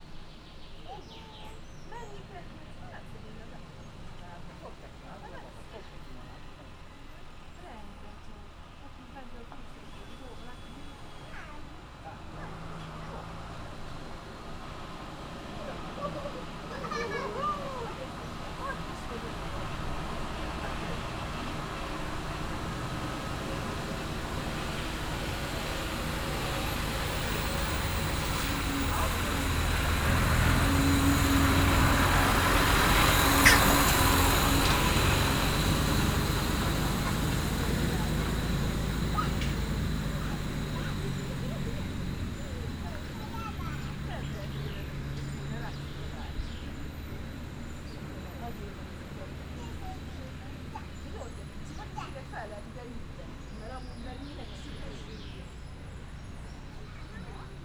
Directory Listing of //allathangok/miskolcizoo2018_professzionalis/csoka/
tovabbraisbejonneki_miskolczoo0057.WAV